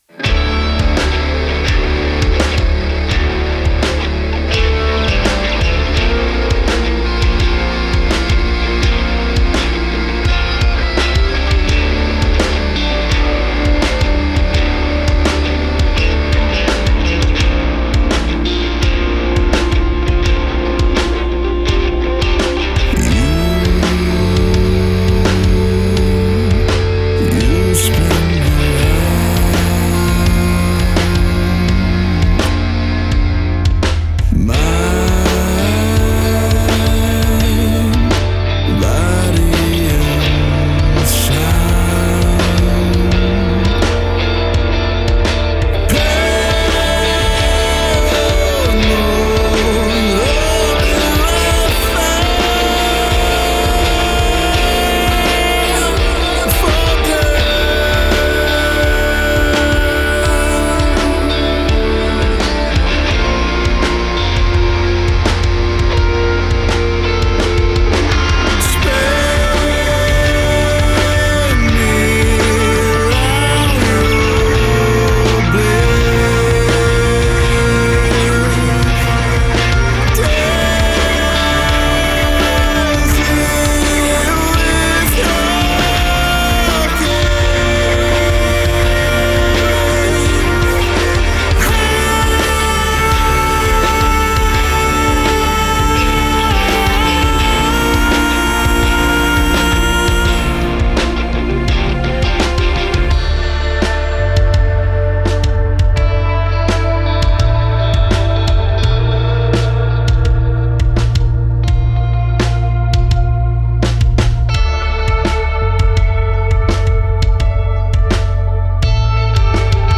drums